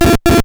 • ▲ ▼ Hallo, Ich habe einen Aurel RX-AM4SF Receiver an die Soundkarte (96kHz) angeschlossen um ein Tschibo-Funkthermometer zu belauschen. Alle 30 Sekunde sendet es 2 Frames direkt nacheinander wie im Audacity-Screenshot zu sehen.